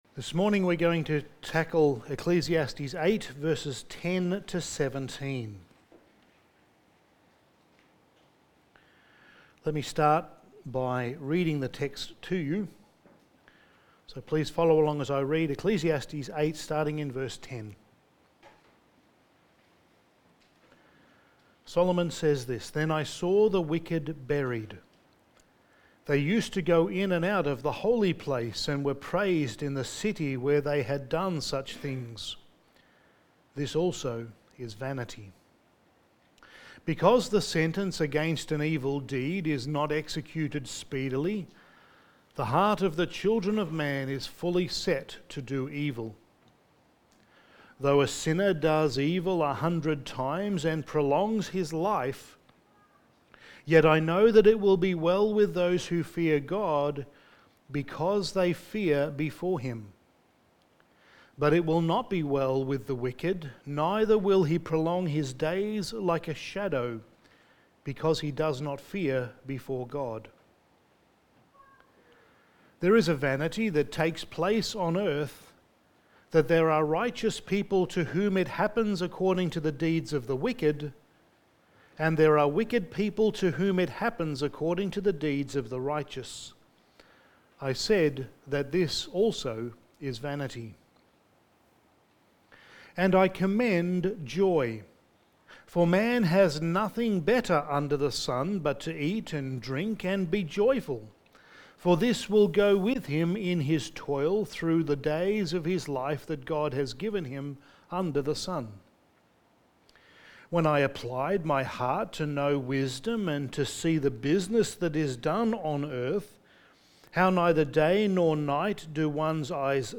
Passage: Ecclesiastes 8:10-17 Service Type: Sunday Morning